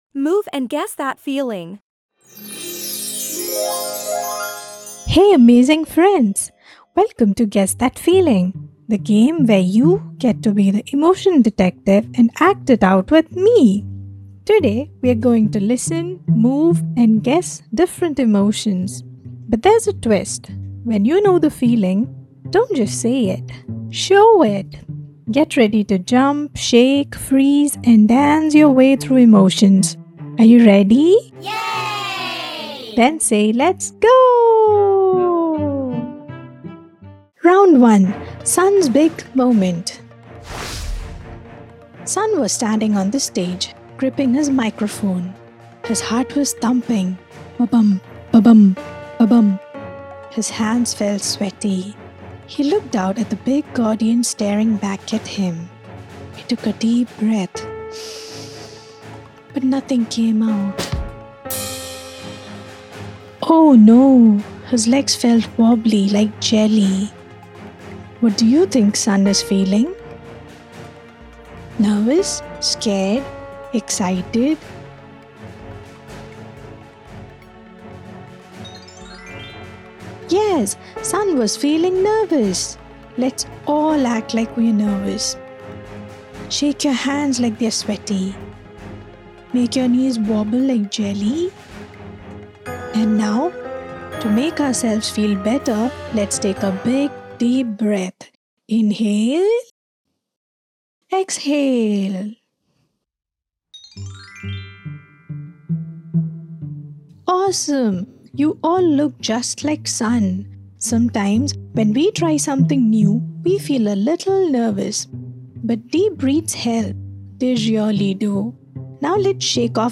move-guess-feeling-read-aloud-podcast.mp3